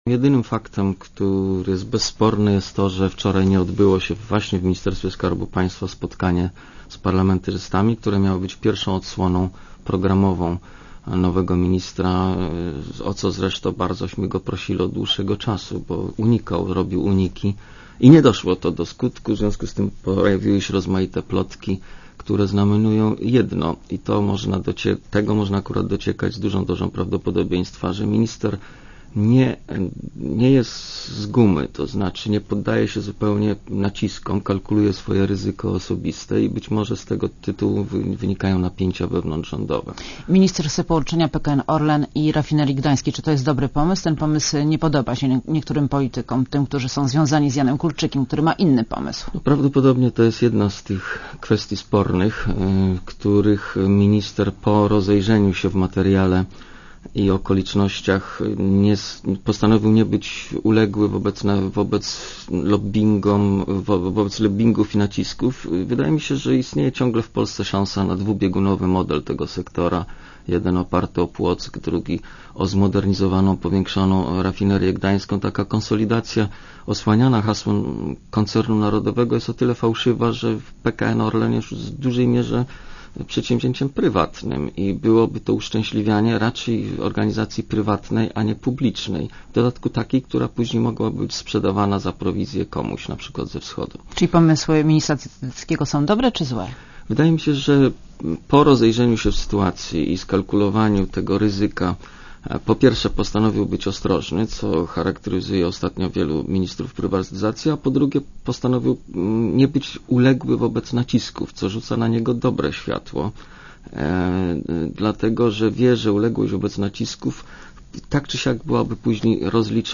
Monika Olejnik rozmawia z Januszem Lewandowskim - posłem Platformy Obywatelskiej